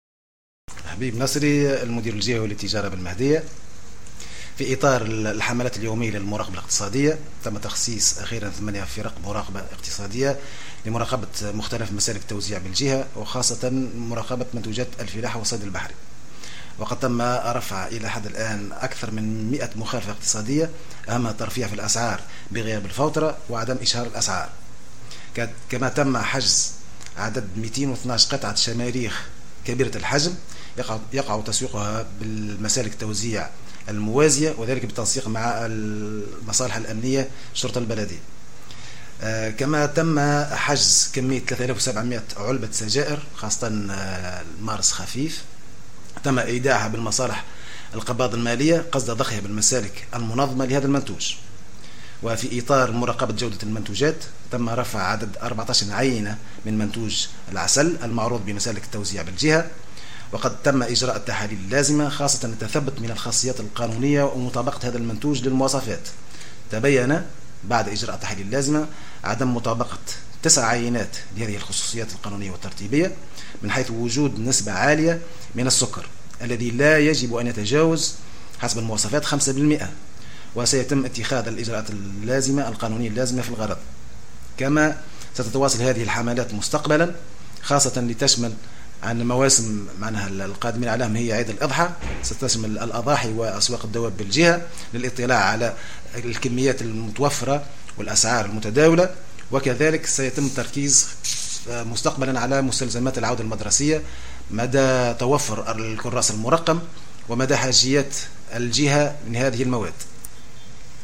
في إطار تواصل الحملات الاقتصادية في ولاية المهدية، أكد المدير الجهوي للتجارة الحبيب النصري في تصريح لمراسل الجوهرة أف أم اليوم الجمعة 19 أوت 2016، أنه تم تخصيص 8 فرق مراقبة اقتصادية قامت بتسجيل أكثر من 100 مخالفة.